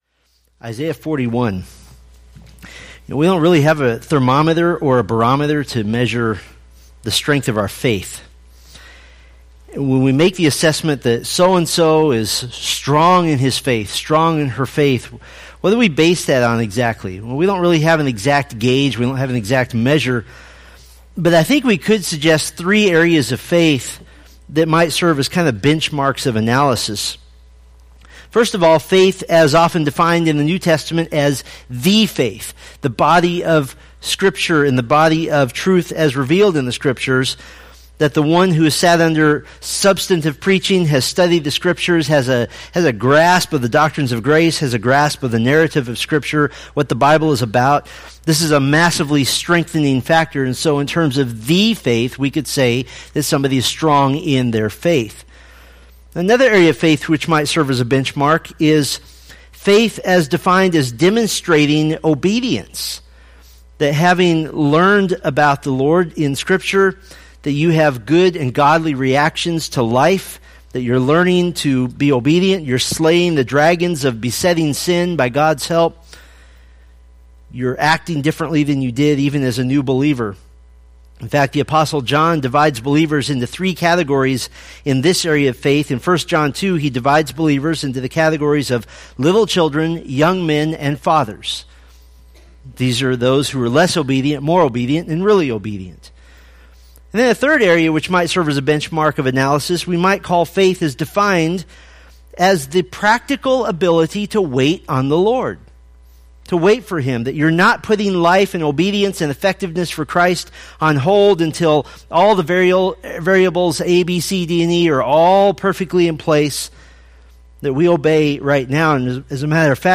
Preached January 22, 2017 from Isaiah 41:1-20